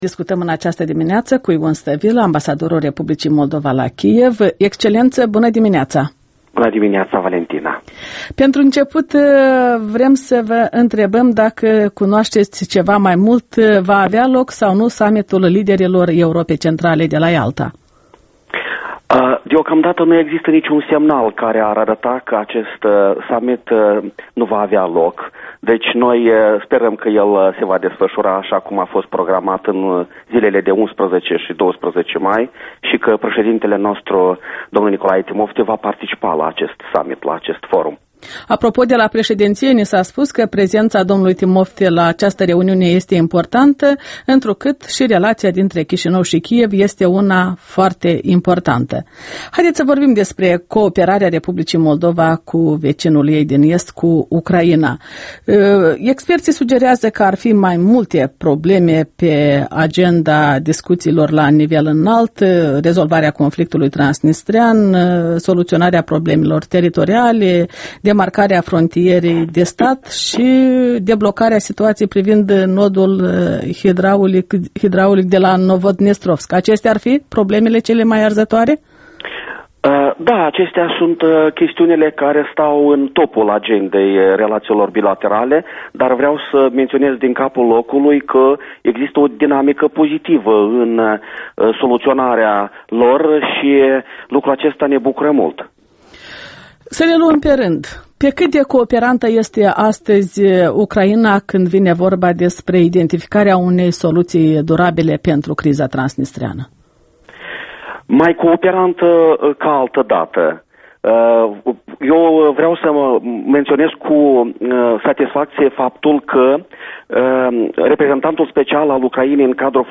Interviul dimineții la EL: cu ambasadorul Ion Stăvilă despre relațiile moldo-ucrainiene